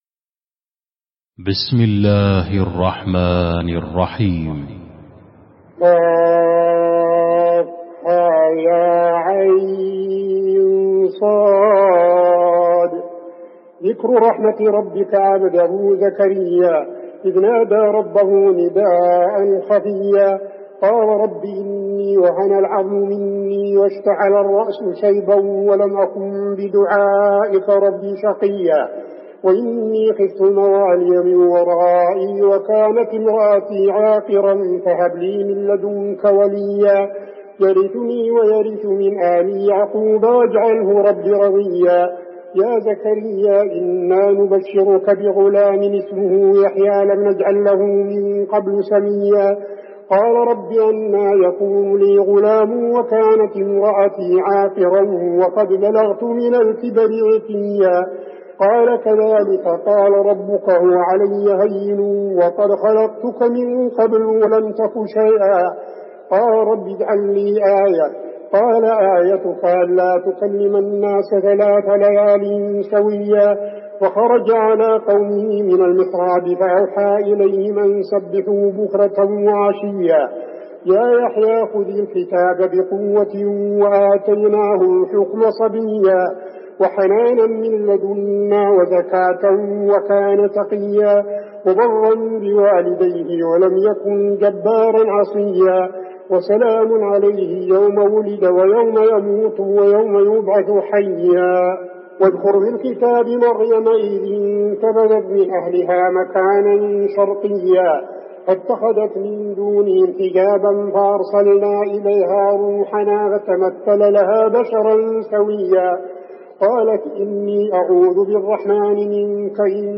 المكان: المسجد النبوي مريم